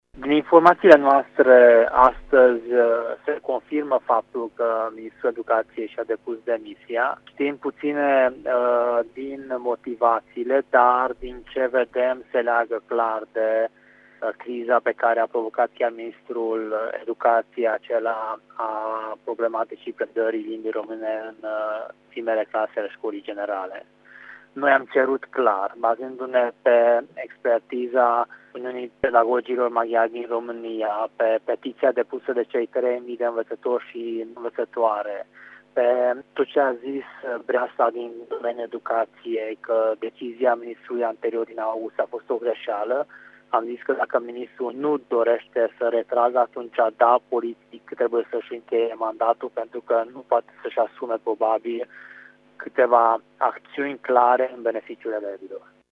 Liderul formațiunii UDMR din Camera Deputaților, Korodi Attila, a declarat pentru Radio Tg.Mureș că problema principală este legată de ordonanţa privind predarea limbii române în şcolile cu predare în limba maternă: